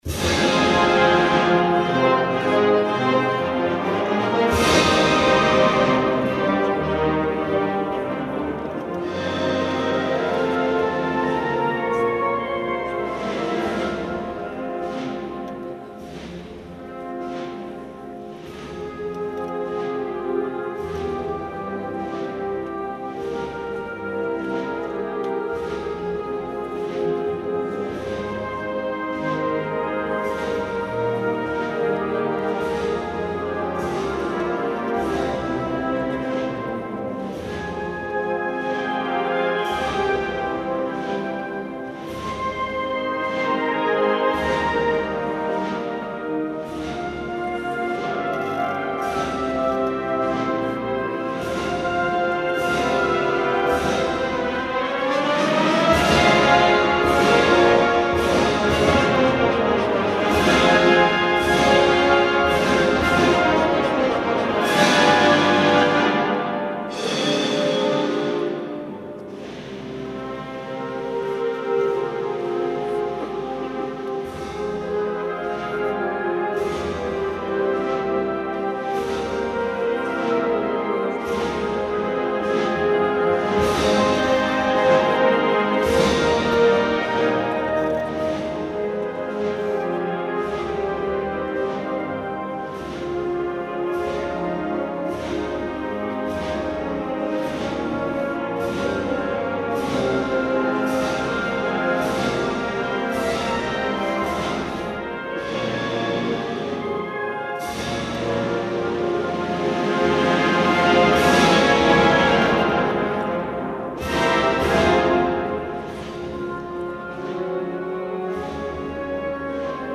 El pasado jueves tuvo lugar en la Catedral de Sevilla el concierto de presentación del nuevo disco de la Banda Sinfónica Municipal de Sevilla.
En el citado concierto, con una Catedral repleta de público, se pudo oír de nuevo la marcha con la instrumentación original tal y como fue concebida por el propio Juarranz.
Los cambios mas notorios se encuentran en los papeles que interpretan las voces secundarias.